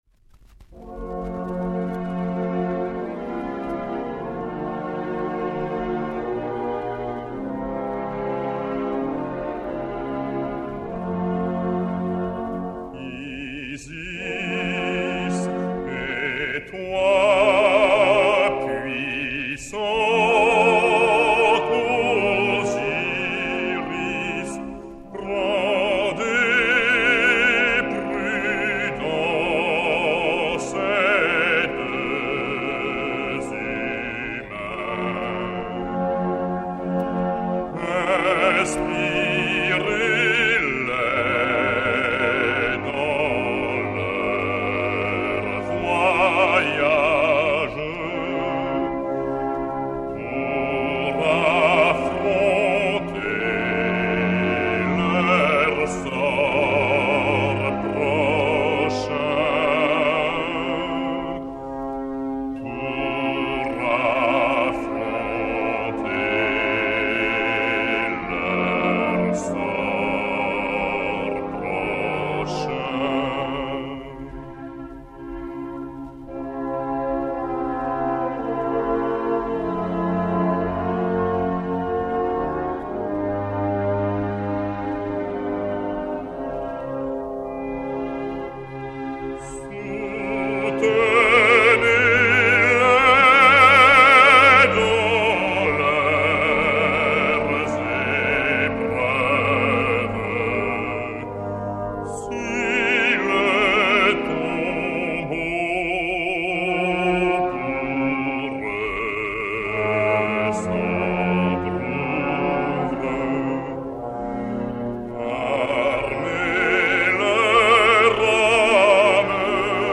Xavier Depraz (Sarastro) et Orch de l'Ass. des Concerts Colonne dir Louis de Froment